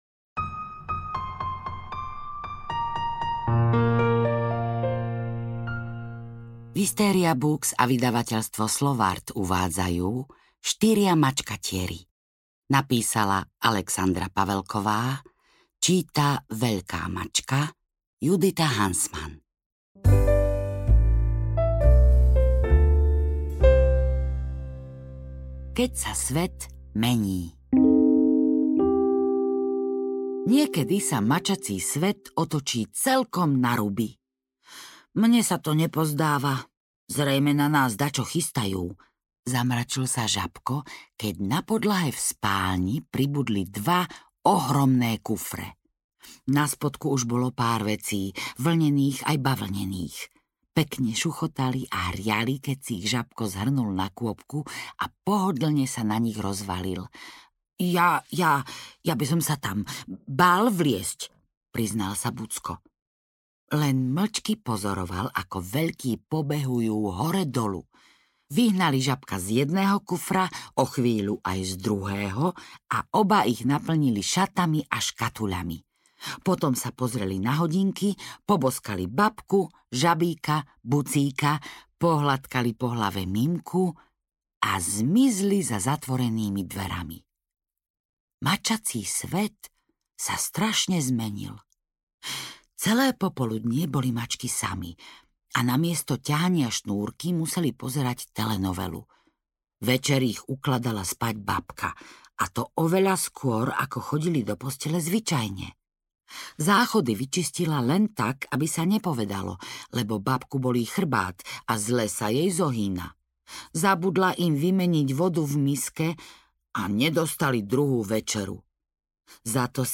Ukázka z knihy
styria-mackatieri-audiokniha